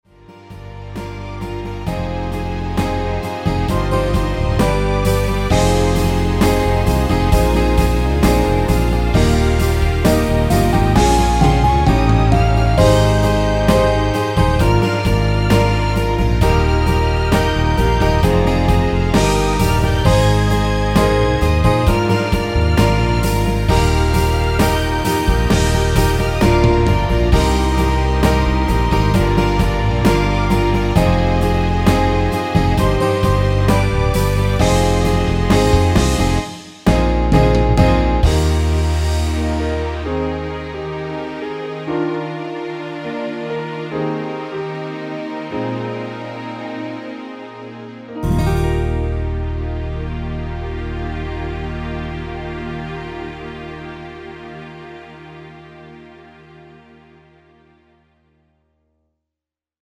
원키에서(+5)올린 (짧은편곡) MR입니다.
Db
앞부분30초, 뒷부분30초씩 편집해서 올려 드리고 있습니다.
중간에 음이 끈어지고 다시 나오는 이유는